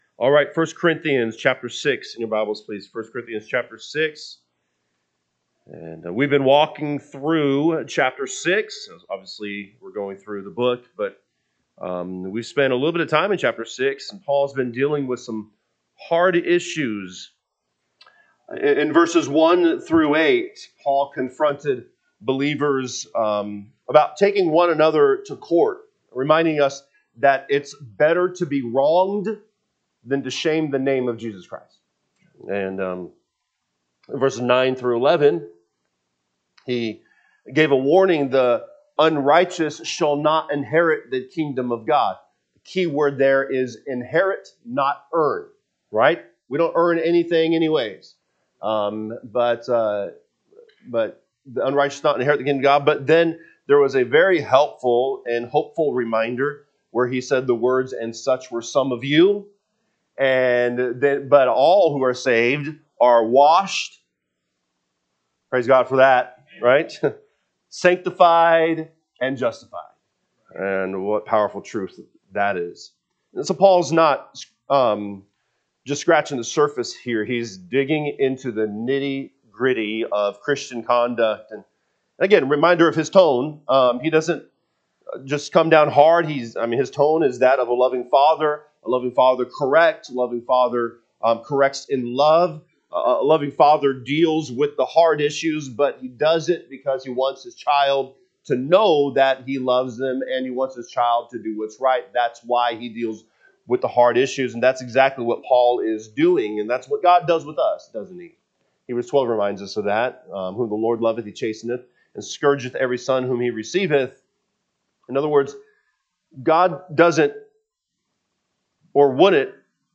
January 11, 2026 pm Service 1 Corinthians 6:12-20 (KJB) 12 All things are lawful unto me, but all things are not expedient: all things are lawful for me, but I will not be brought under the po…
Sunday PM Message